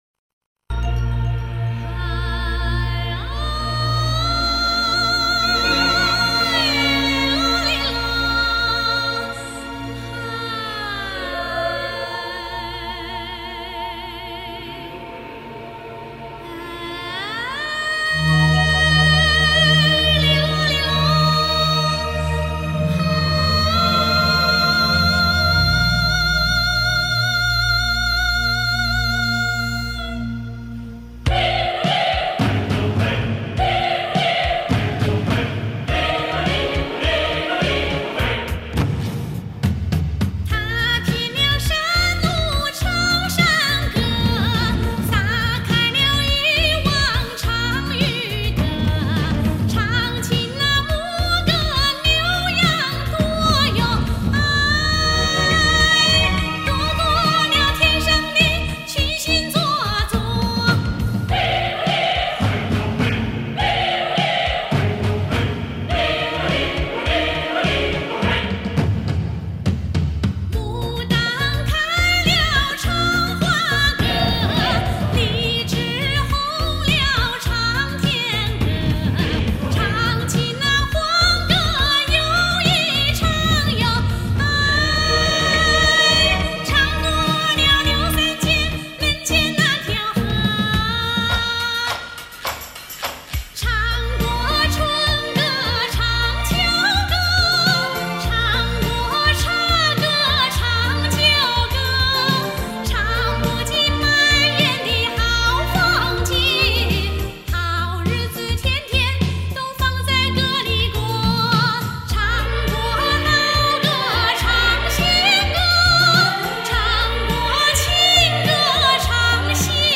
翻唱
巨大美贴，嗓子也美！
惊叹，转调精准，音感和乐感一流！